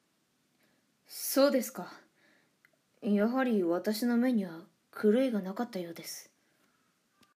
サンプルボイス クール 【少年】